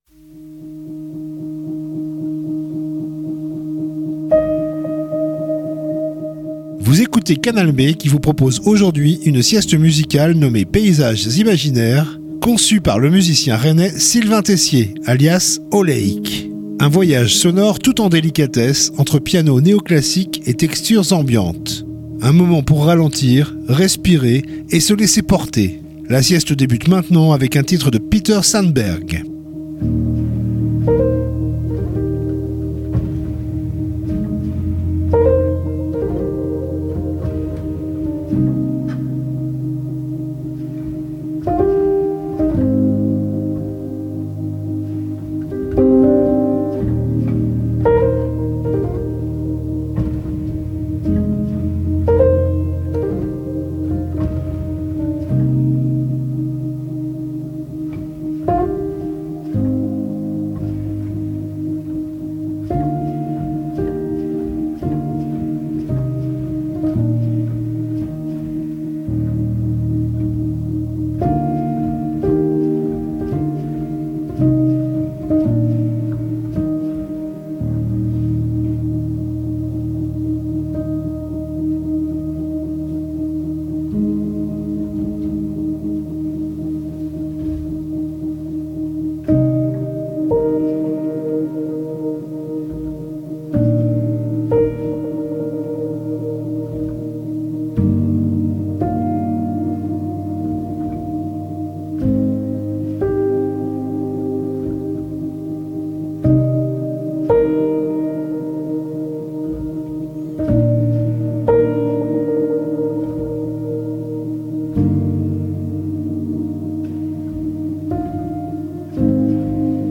musiques instrumentales et intimistes